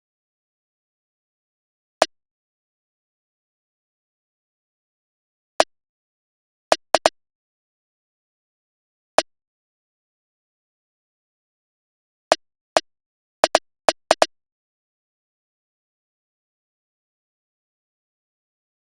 drill (!)_[PBS] Compressed Snare_2.wav